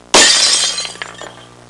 Breaking Glass Sound Effect
Download a high-quality breaking glass sound effect.
breaking-glass-2.mp3